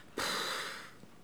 pfff-soulagement_01.wav